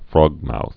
(frôgmouth, frŏg-)